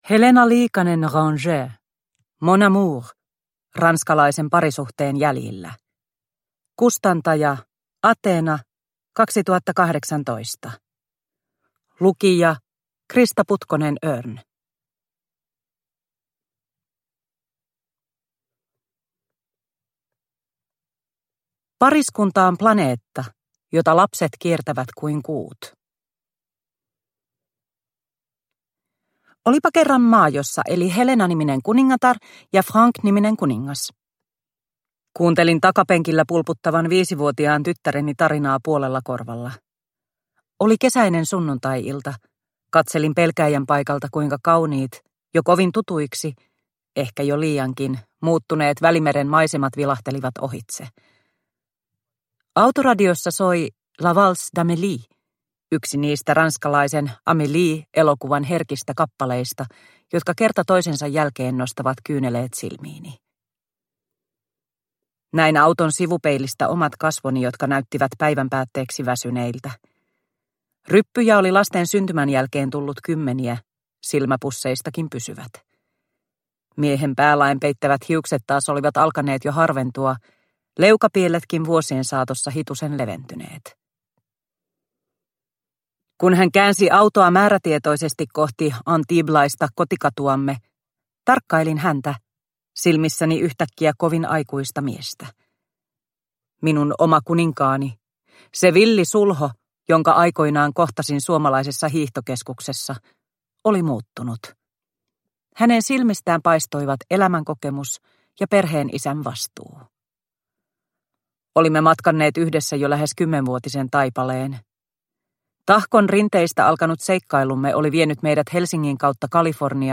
Mon amour – Ljudbok – Laddas ner